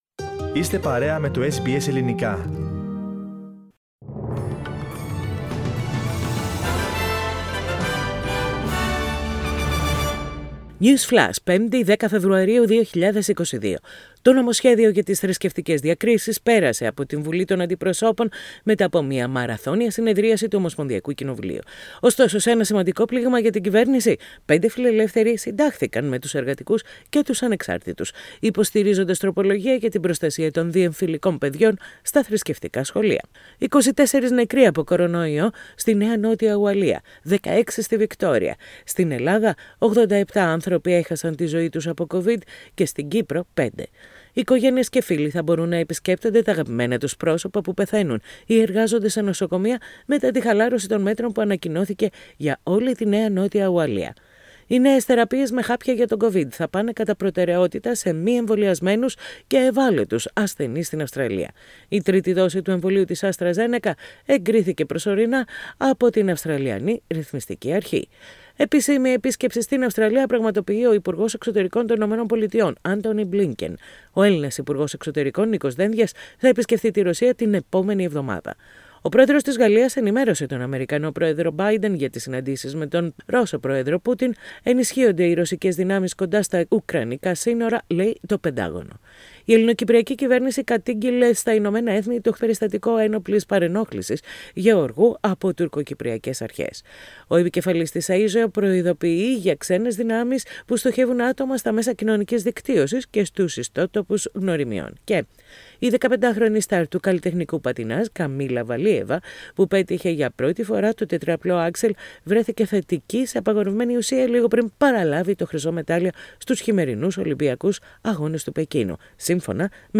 News Flash in Greek Source: SBS Radio